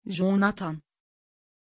Pronunciación de Joonatan
pronunciation-joonatan-tr.mp3